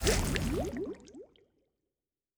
Fantasy Interface Sounds
Potion and Alchemy 13.wav